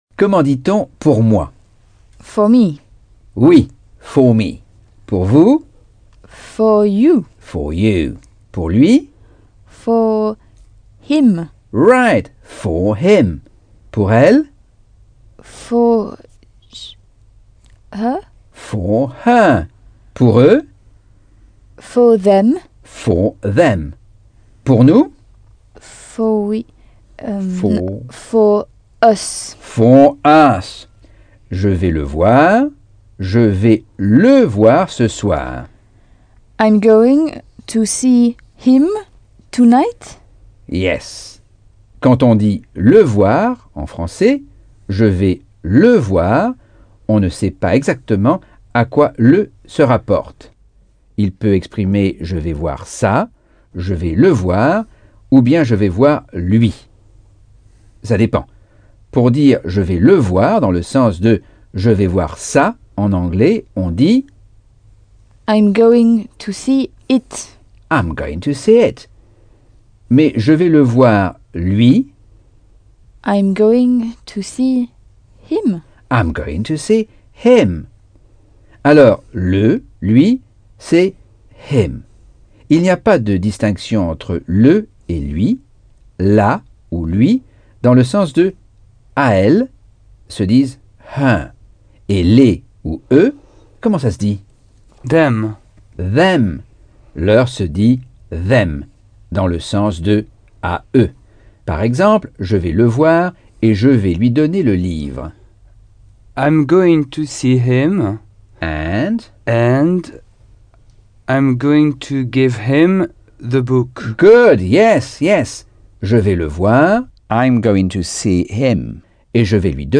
Leçon 9 - Cours audio Anglais par Michel Thomas